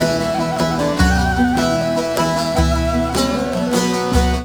SAZ 03.AIF.wav